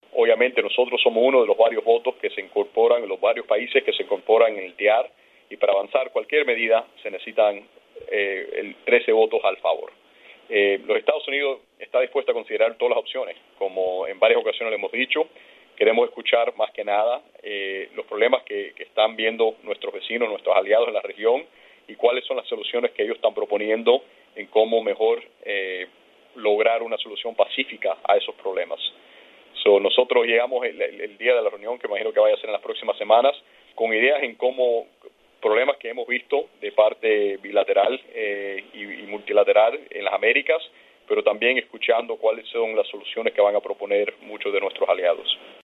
Dos minutos antes de la hora fijada para una conferencia de prensa telefónica de Trujillo sobre la convocatoria de 11 países del hemisferio para aplicar el TIAR en Venezuela (en el marco de la OEA), el presidente Donald Trump anunció en un mensaje de Twitter el despido del asesor de Seguridad Nacional John Bolton, uno de los artífices de la política de la Casa Blanca hacia el régimen de Maduro.